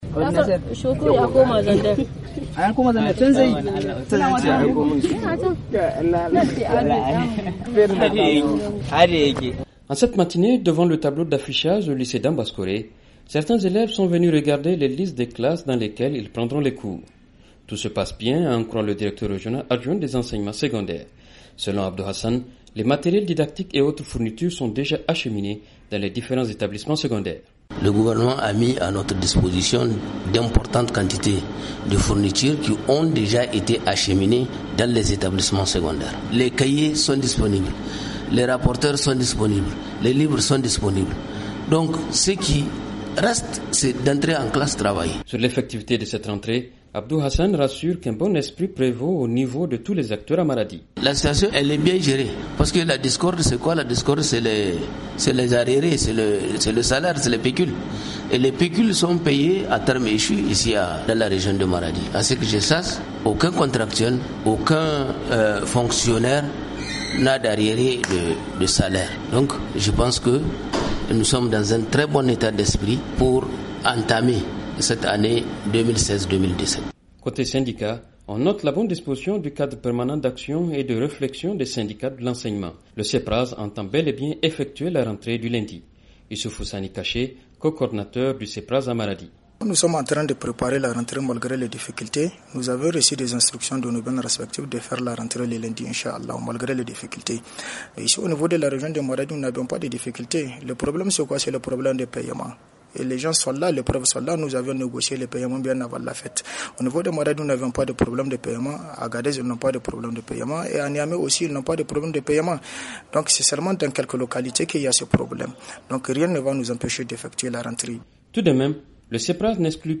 Nous avons joint au téléphone le secrétaire […]